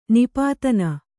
♪ nipātana